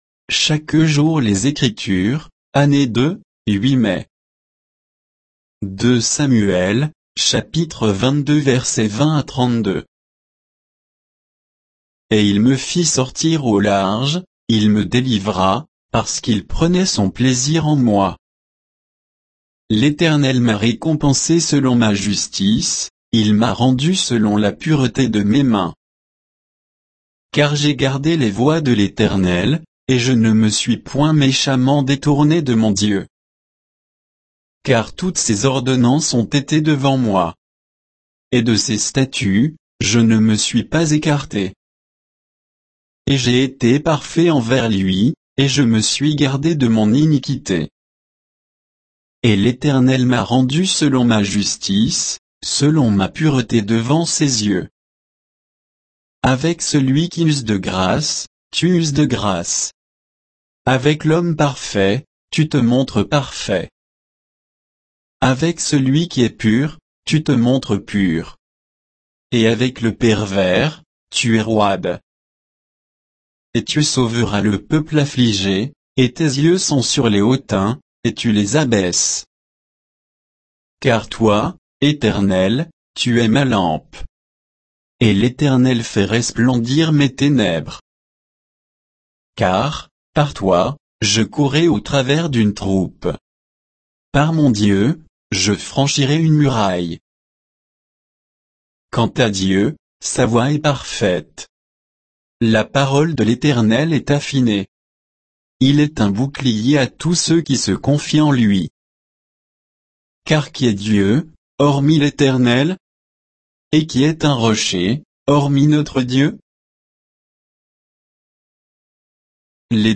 Méditation quoditienne de Chaque jour les Écritures sur 2 Samuel 22